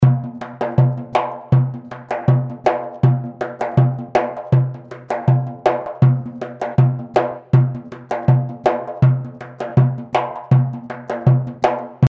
bendir2.mp3